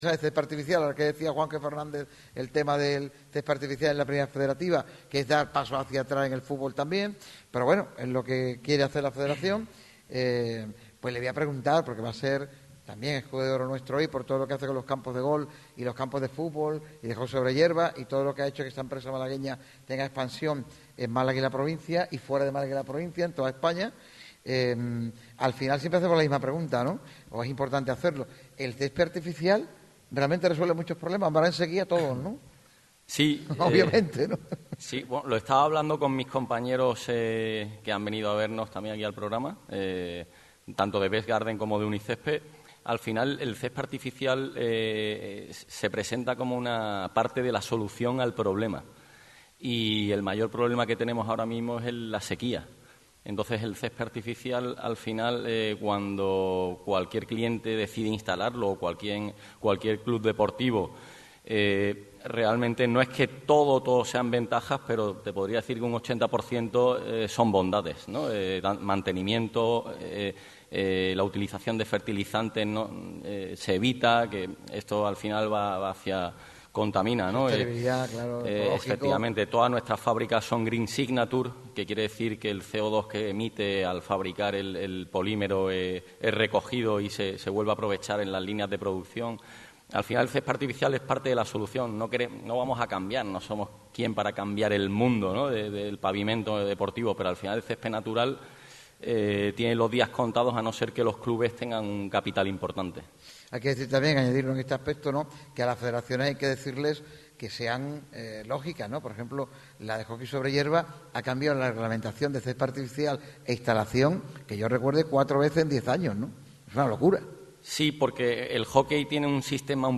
Fran Vázquez, mítico ex jugador del Unicaja, afirma que sería increíble ganarla dos veces seguidas la Copa del Rey. El pívot pasó por el micrófono rojo en un programa especial en el Auditorio de la Diputación de Málaga.